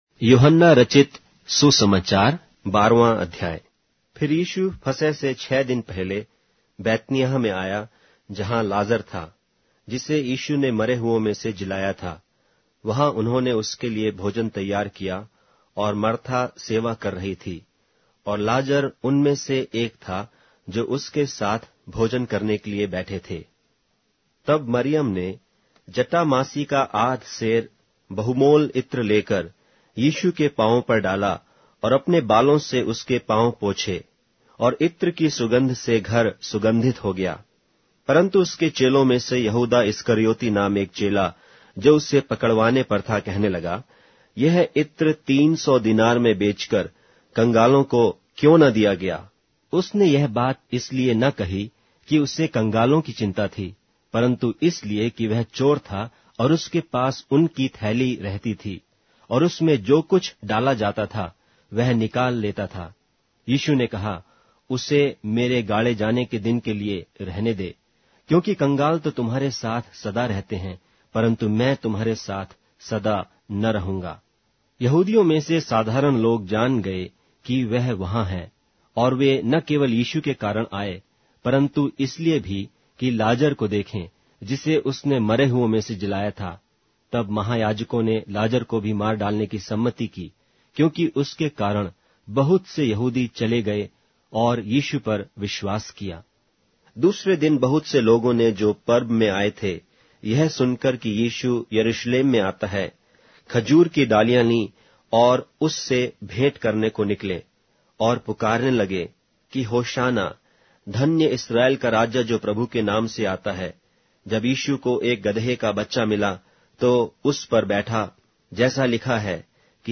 Hindi Audio Bible - John 16 in Hcsb bible version